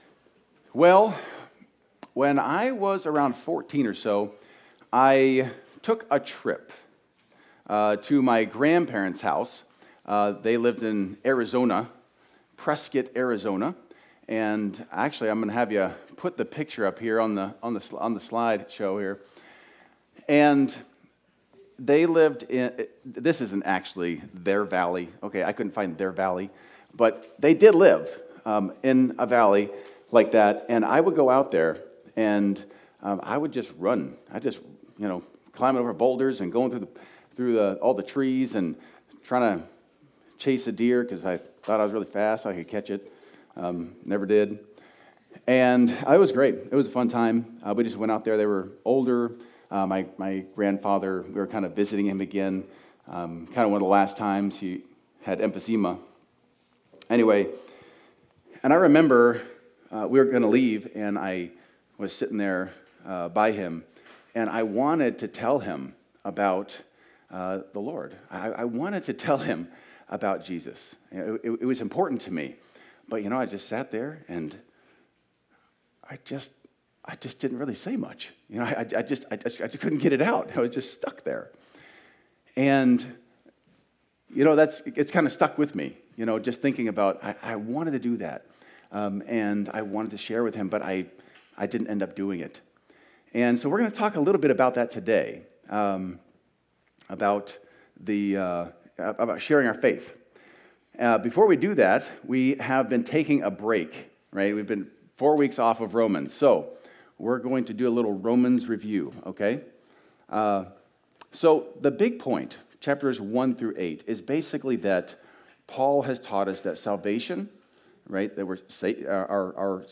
Video Audio Download Audio Home Resources Sermons The Unbelievers Predicament: Paul’s Persuasive Prayer Nov 09 The Unbelievers Predicament: Paul’s Persuasive Prayer Today we discuss Christians in the Roman church.